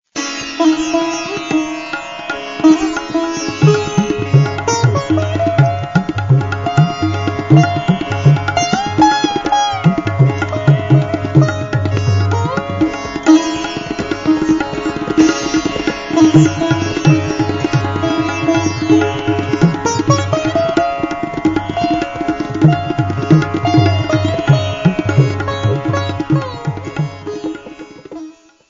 mishra_shivaranjani4.mp3